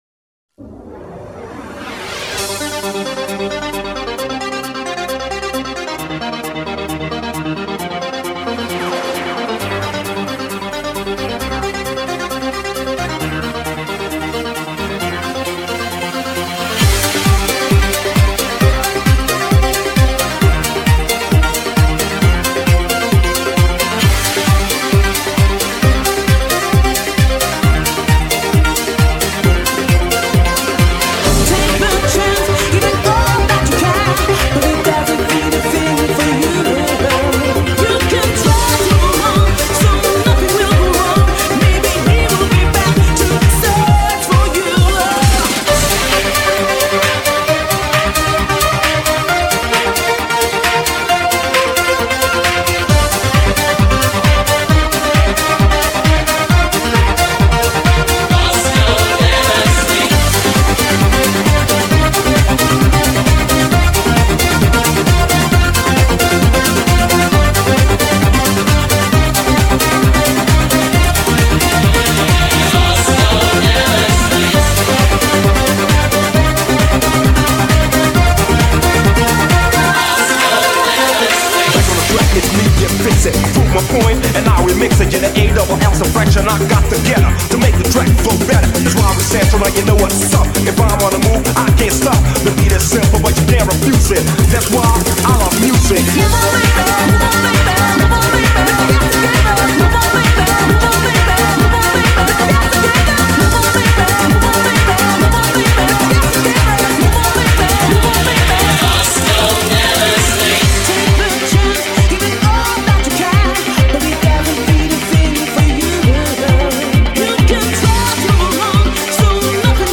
Назад в ¤Super / Club / Dance¤
*** Прикольный микс...